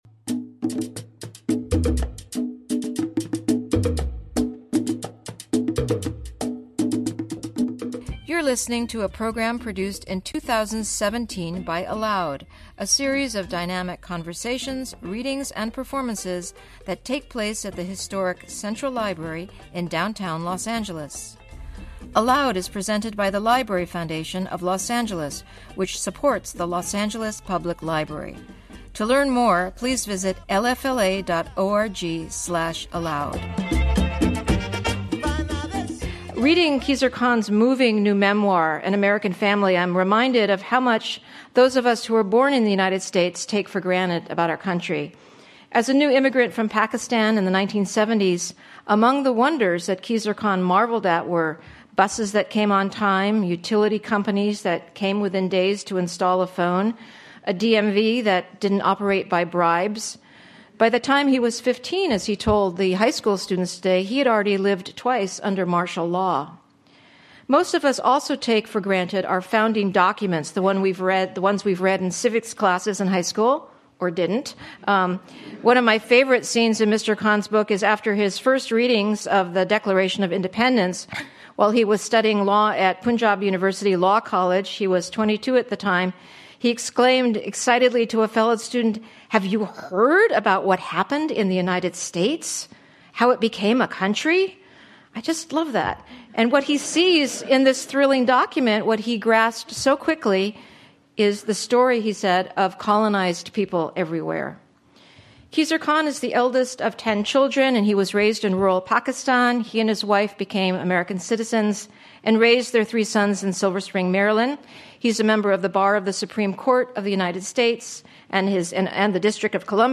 Khizr Khan In Conversation
Khan will now take the ALOUD stage to discuss the realities of life in a nation of immigrants and the daily struggles of living up to our ideals.